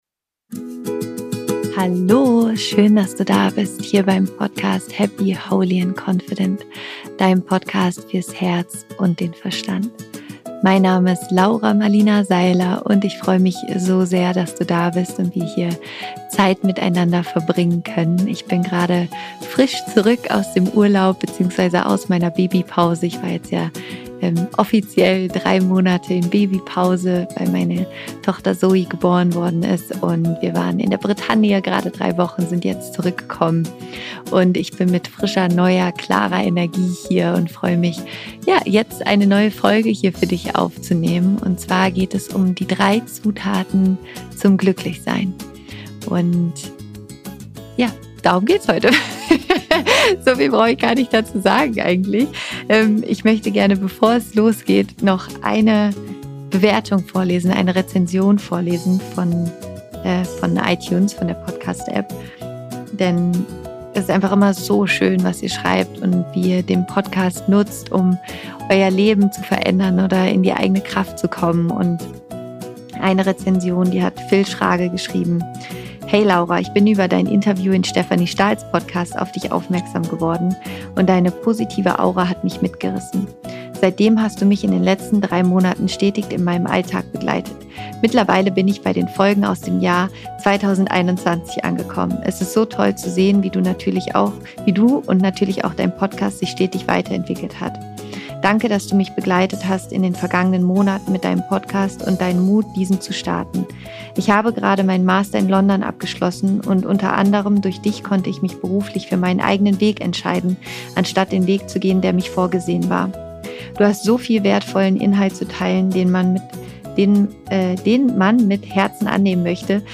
Am Ende der Folge wartet eine wunderschöne, kurze Meditation auf dich, in der du direkt in dein Glück eintauchen kannst.